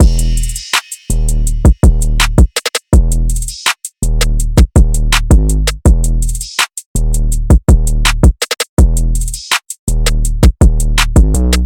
Preme (164 BPM – Abm)
UNISON_DRUMLOOP_Preme-164-BPM-Abm.mp3